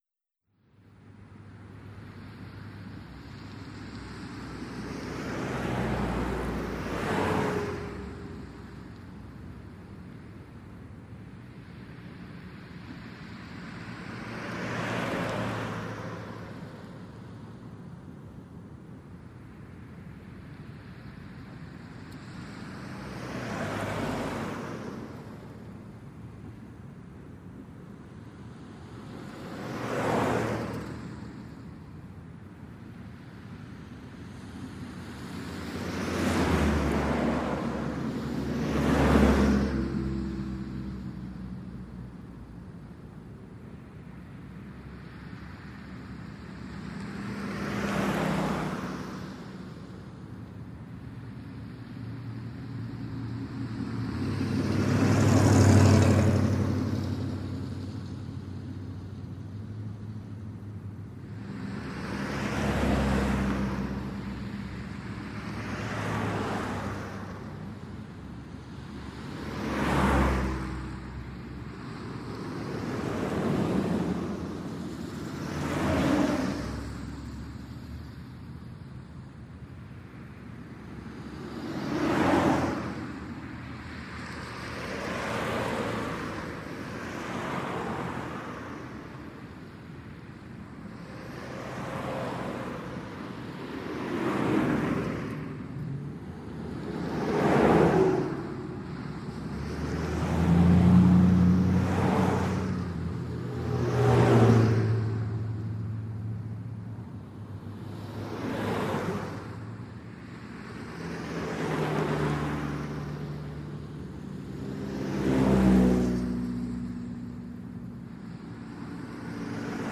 Ambient_ExteriorLoop.wav